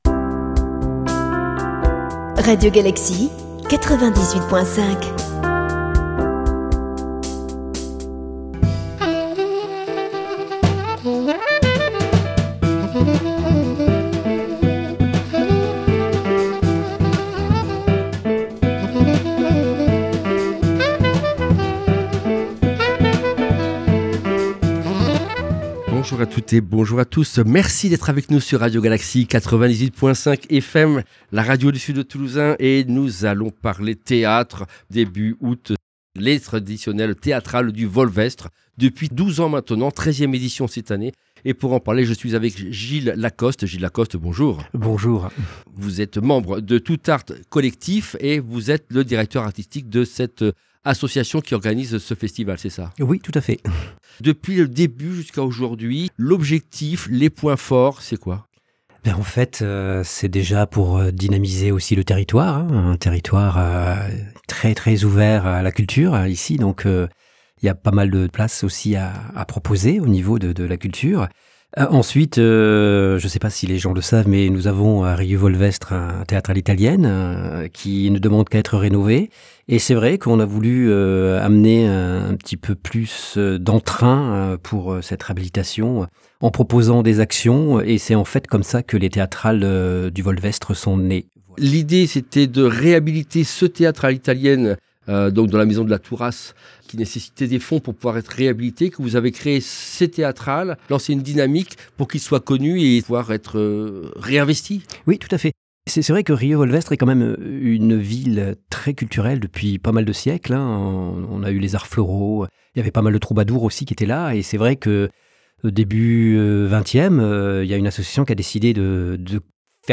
Genre : Interview.